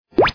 dianji.mp3